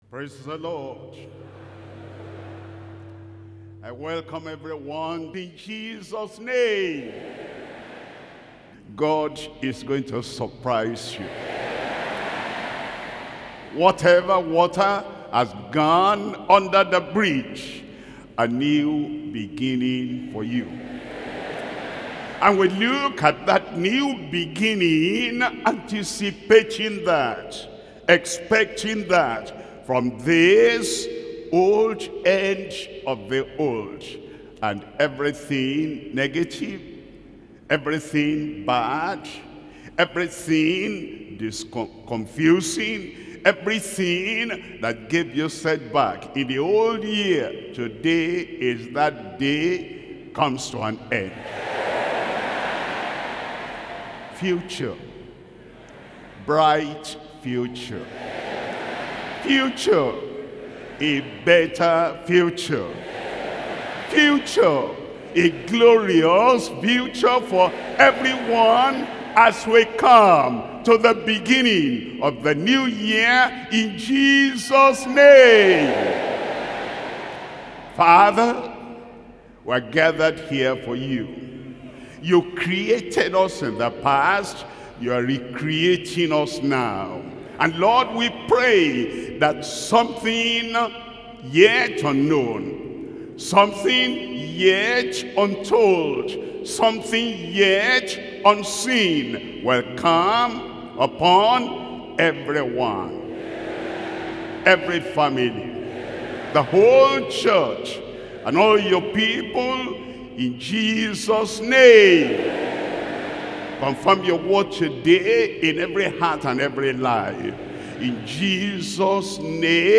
Sermons - Deeper Christian Life Ministry
Watch Night Service